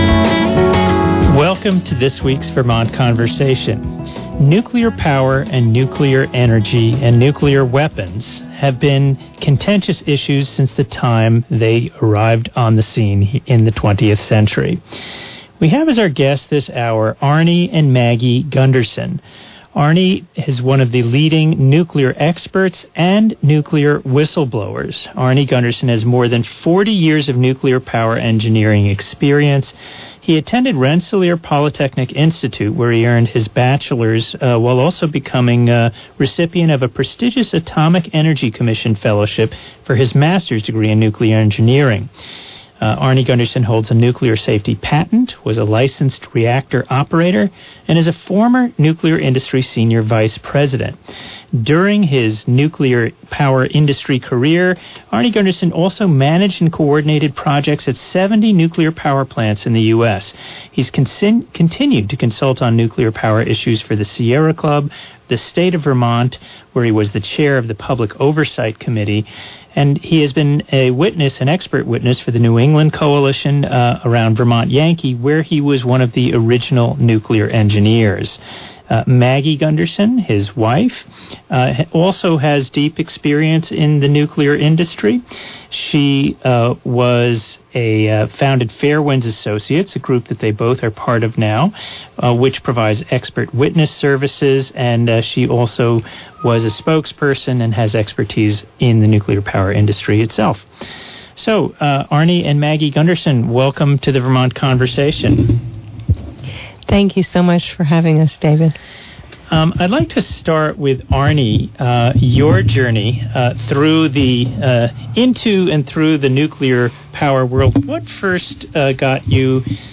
(August 17, 2016 broadcast)